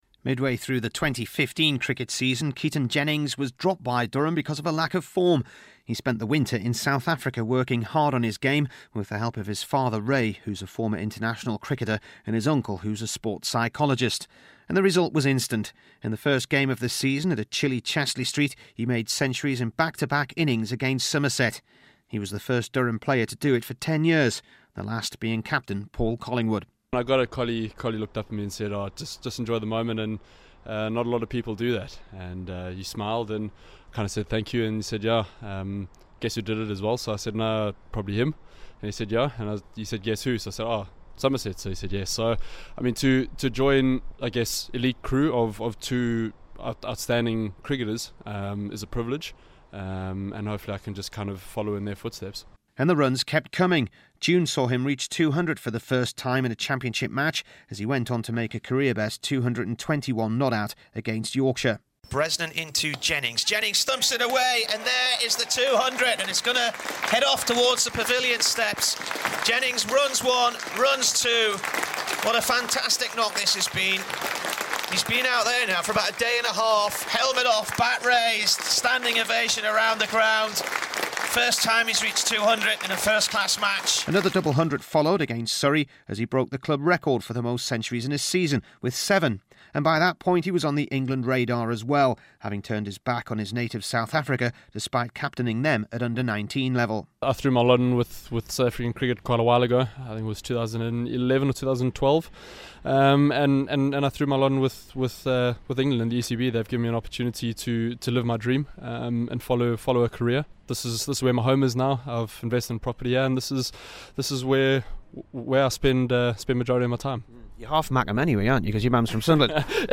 HERE'S MY REPORT ON KEATON JENNINGS' RISE FOLLOWING HIS CALL-UP BY ENGLAND.